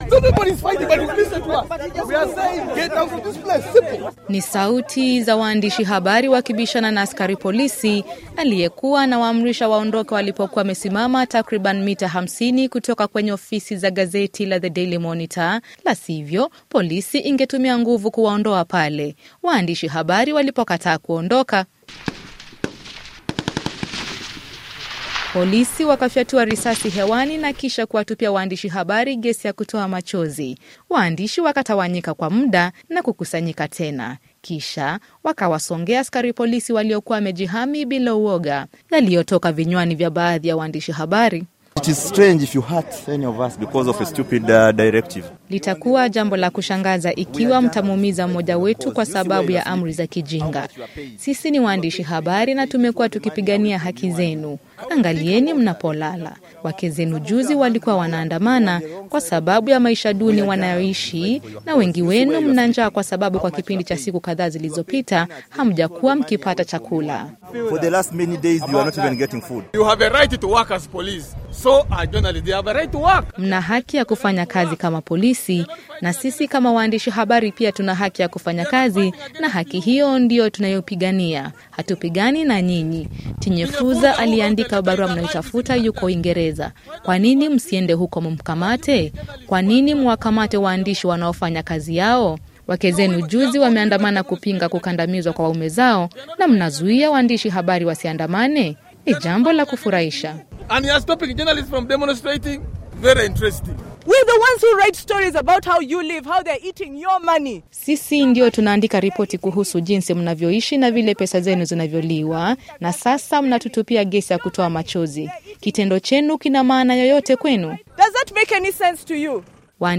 Ripoti